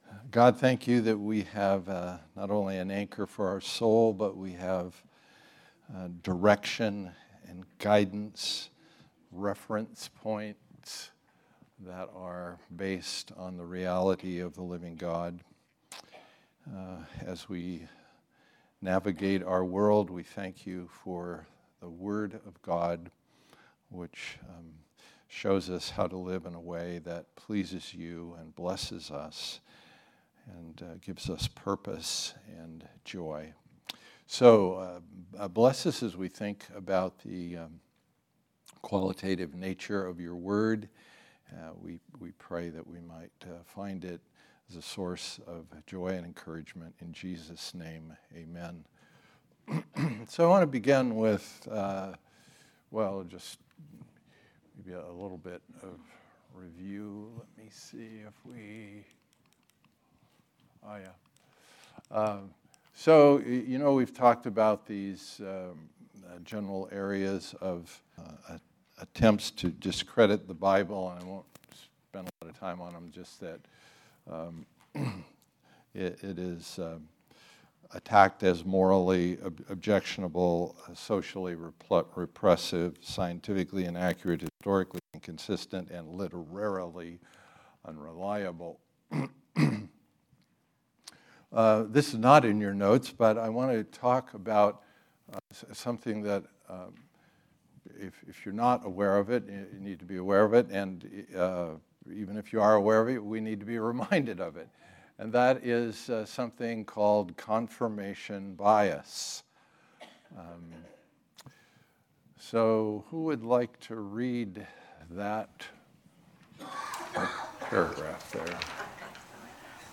2023 Series: Trustworthy Type: Sunday School